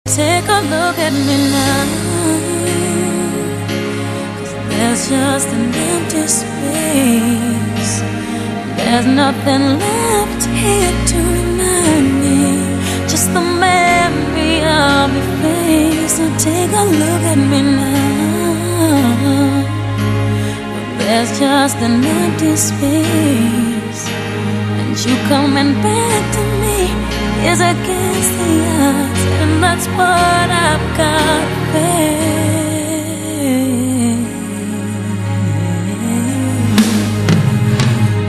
M4R铃声, MP3铃声, 欧美歌曲 23 首发日期：2018-05-15 13:42 星期二